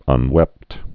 (ŭn-wĕpt)